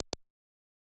flip.ogg